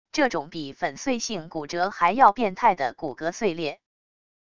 这种比粉碎性骨折还要变态的骨骼碎裂wav音频